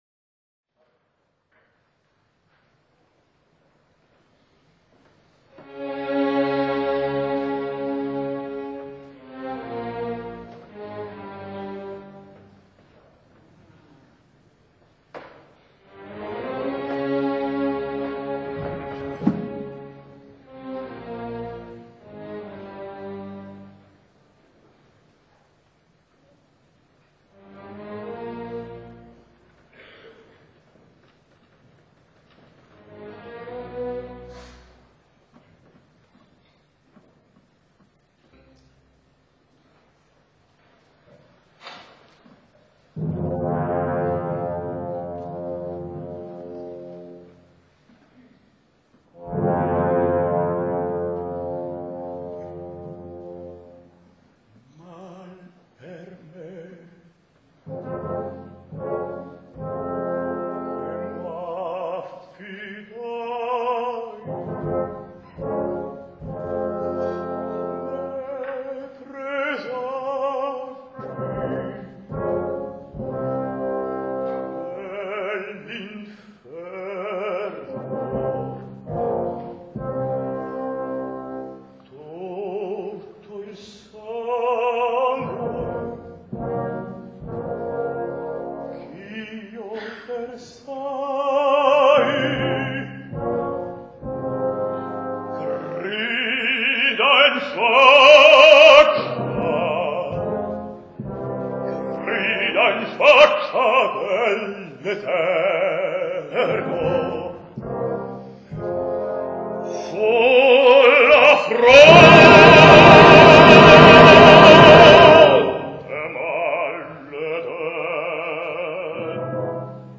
baryton
OPERA